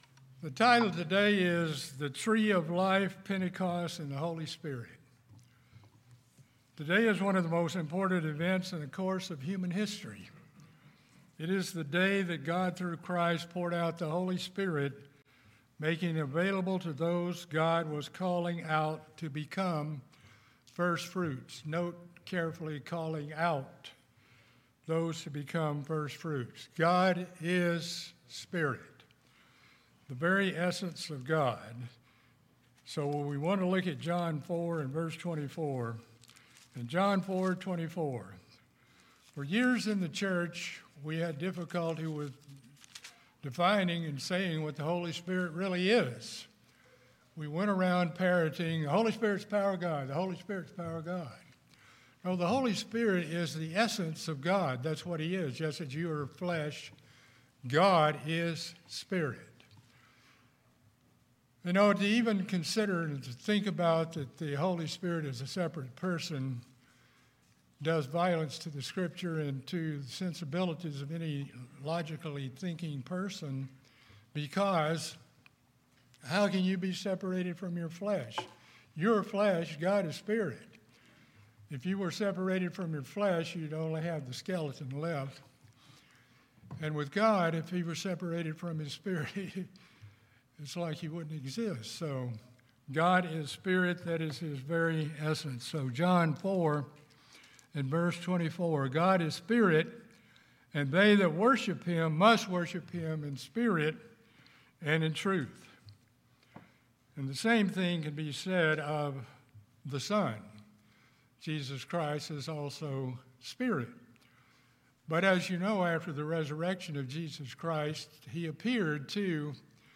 In this sermon the relationship of Pentecost, the Tree of Life and the Holy Spirit is discussed.